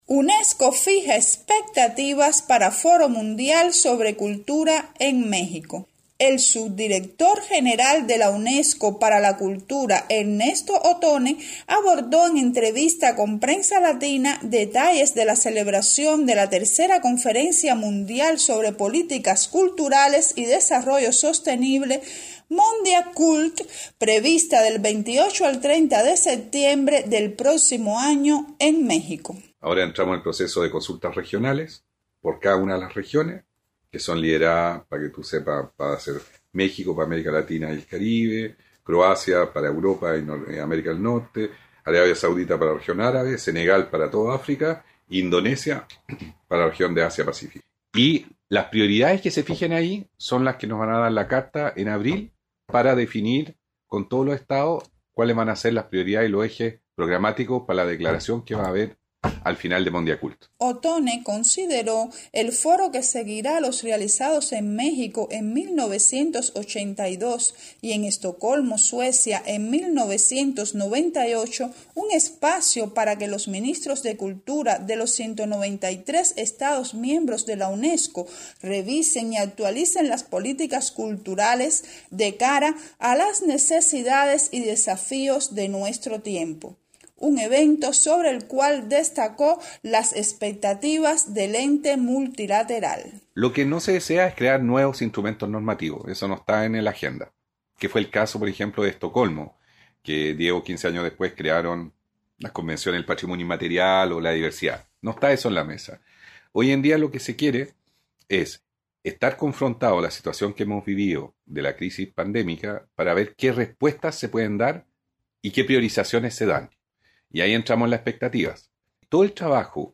El subdirector general de la Unesco para la Cultura, Ernesto Ottone, abordó en entrevista con Prensa Latina detalles de la celebración de la tercera Conferencia Mundial sobre Políticas Culturales y Desarrollo Sostenible (Mondiacult), prevista del 28 al 30 de septiembre del próximo año en México.